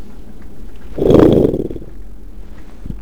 Fred the lion roars